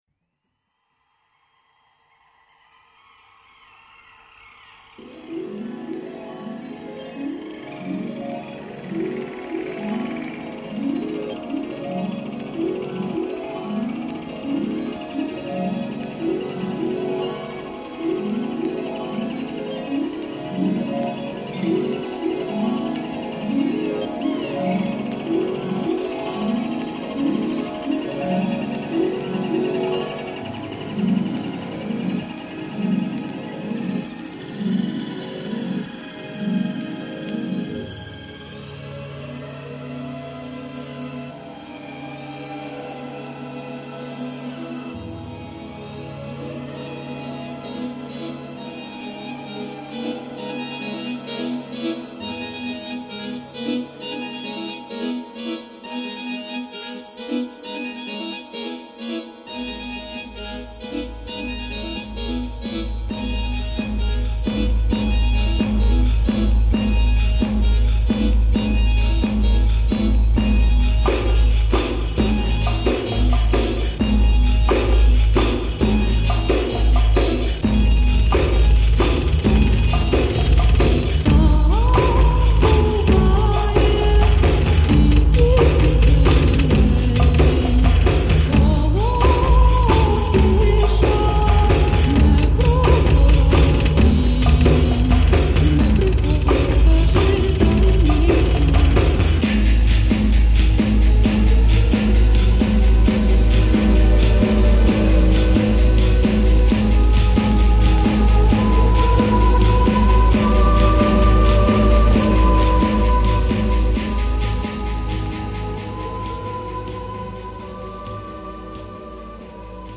Electronix Wave Pop